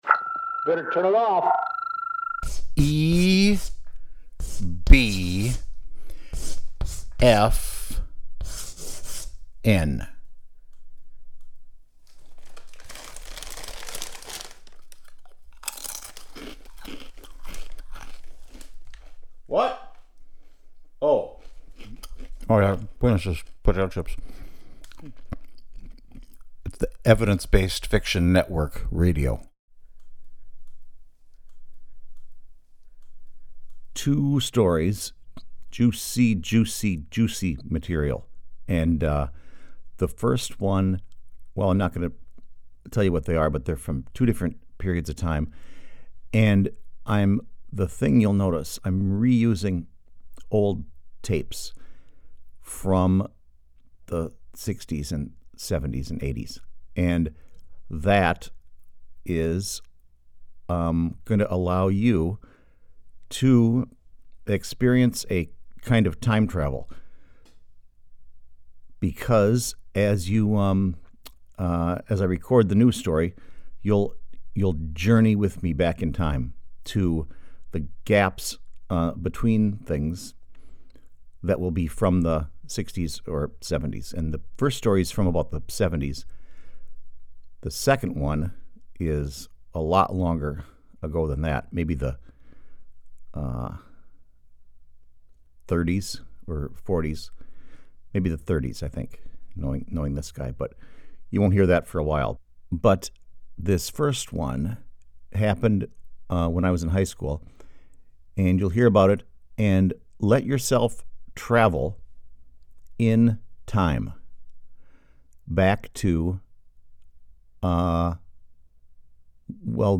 Again, just my luck, this unreliable and noisy recording technology further strains my recall of these two childhood classics. Plus I had to re-use old cassette tapes to record it.